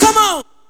VOX SHORTS-2 0002.wav